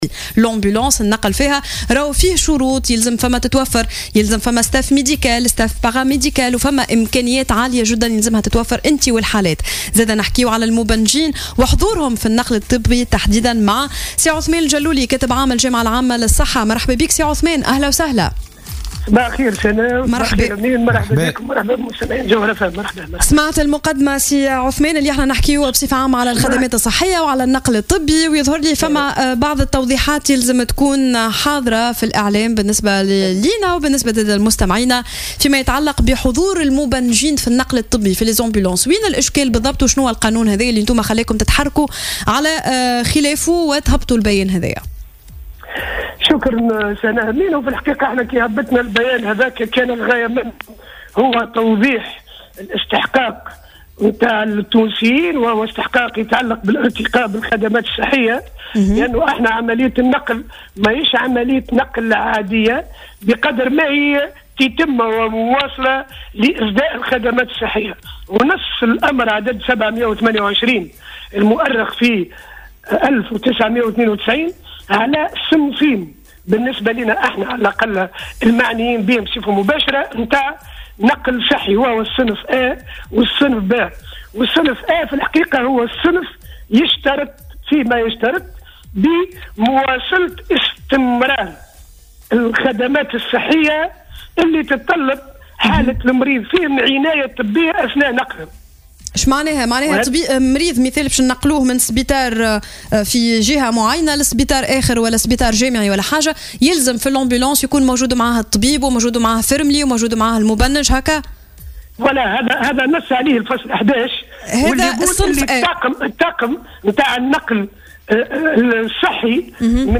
وأوضح أن القوانين التنظيمية للقطاع الصحي تشترط في النقل الطبي من صنف "أ" وجود طاقم طبي يتكون من طبيب ومبنّج أساسا وهي شروط لا يتم احترامها، بحسب تأكيداته لـ"الجوهرة أف أم" من خلال برنامج "صباح الورد".